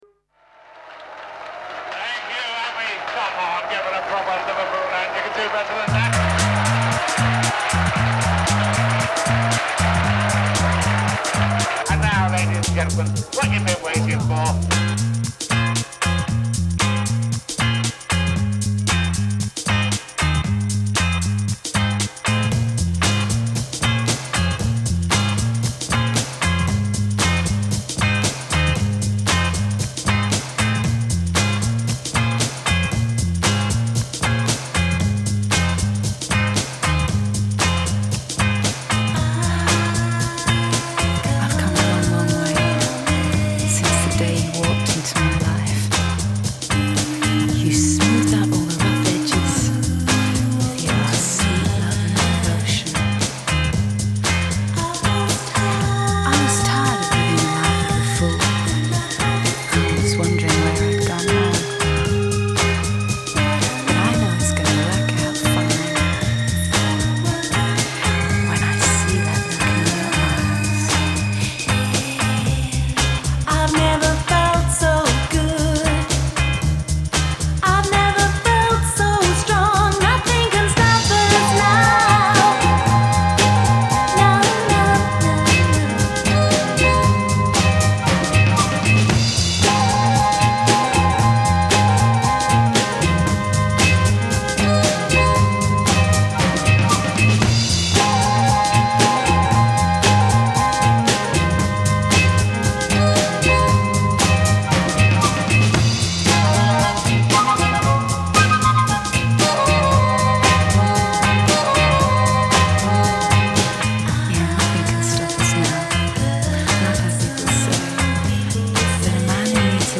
Жанр: Pop, Alternative, Indie Pop, Synthpop, Dance-Pop